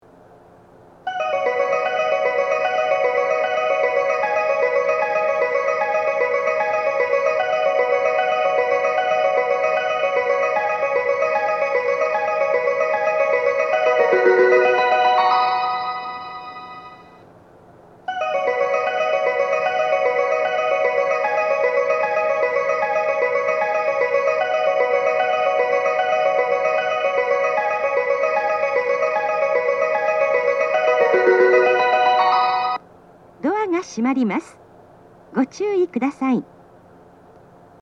1.9コーラス